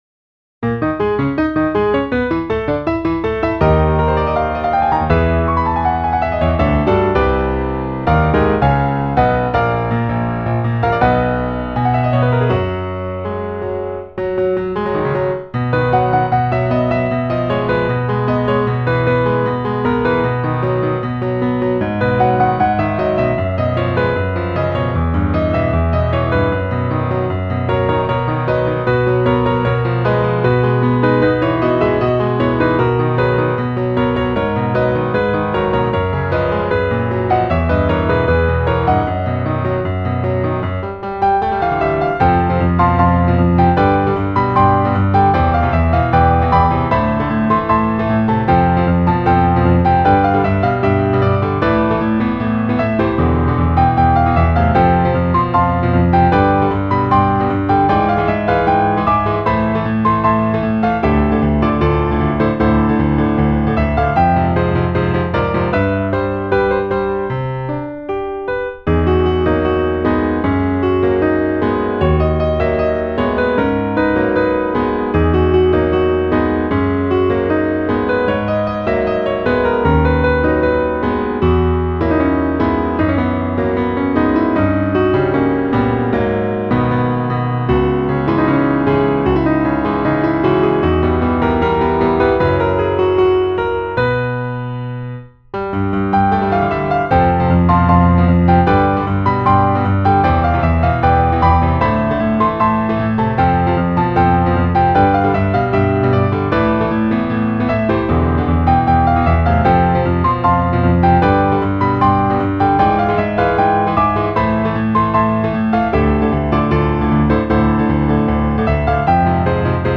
PIANO部屋 新着10曲分・一覧表示は こちら